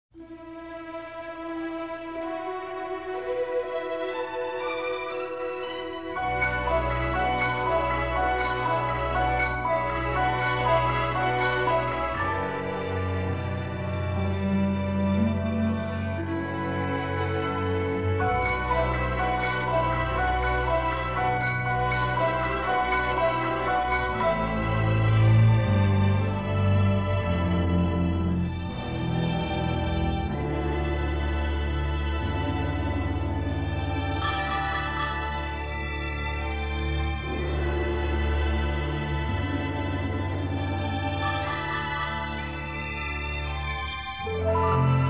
haunting Asian textures and harmonies
The entire CD is in stereo